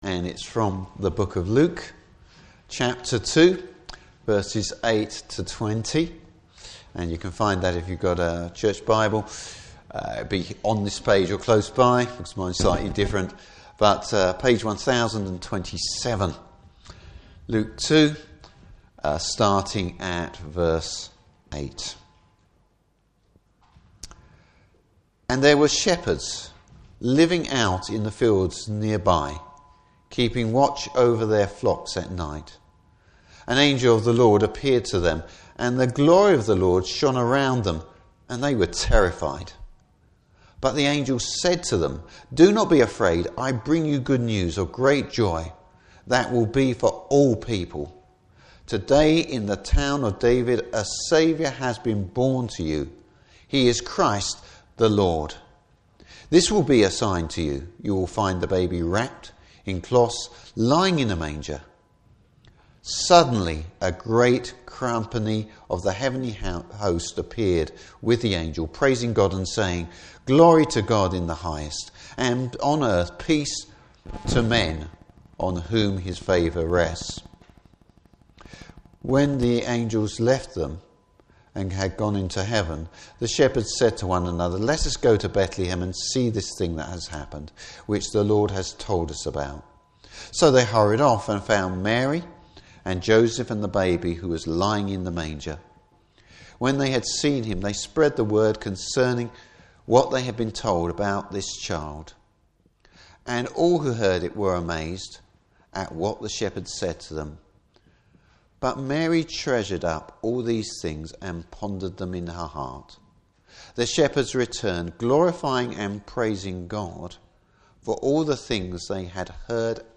Service Type: Christmas Day Morning Service.